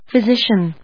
音節phy・si・cian 発音記号・読み方
/fɪzíʃən(米国英語)/